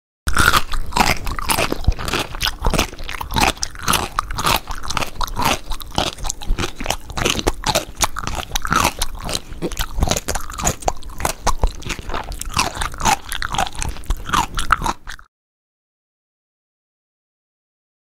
Eating Sound Effect Free Download
Eating